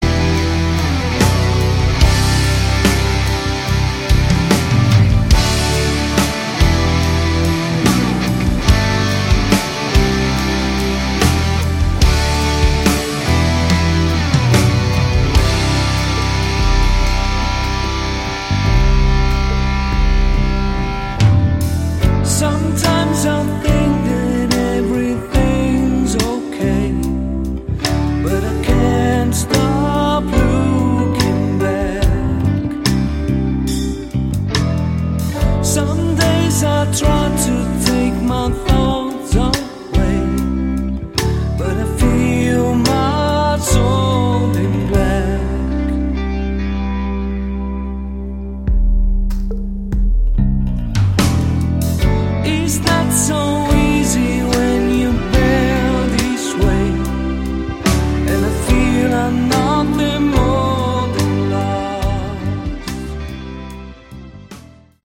Category: Melodic Rock
lead and backing vocals
guitars, keyboards, violin